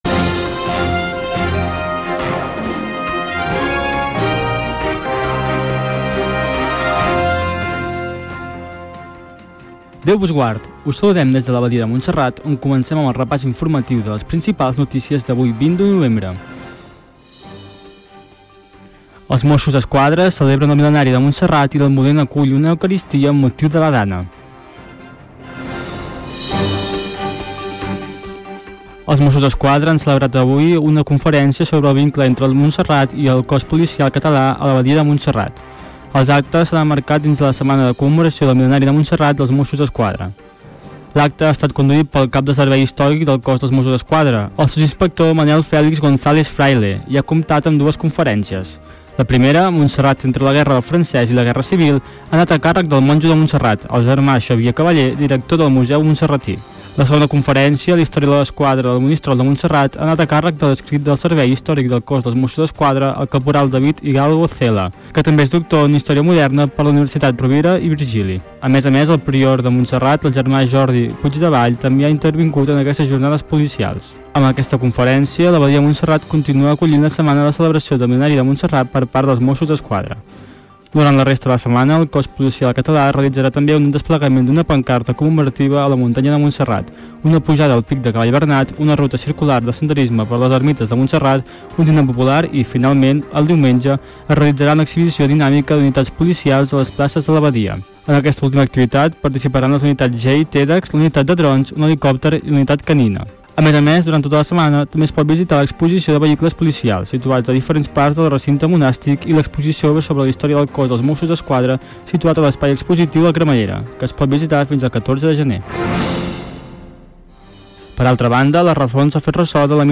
Sintonia, data, titulars, els Mossos d'Esquadra commemoren el mil·lenari del monestir de Montserrat, missa a l'Almudena (Madrid) per la catàstrofe de la DANA a València, comiat i sintonia
Informatiu